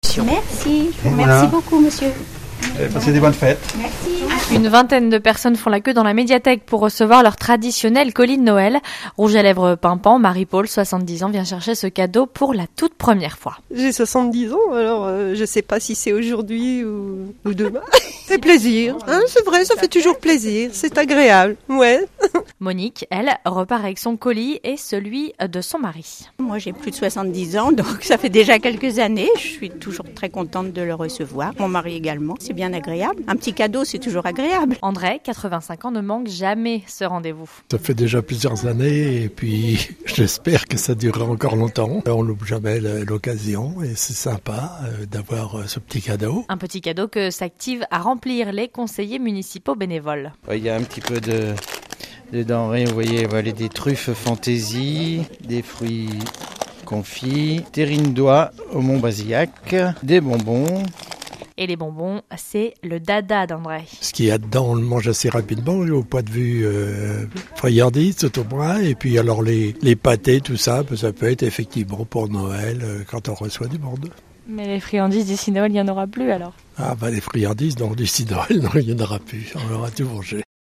C'est la période de distributions des colis de Noël sur la Côte d'Opale. Reportage dans le Montreuillois.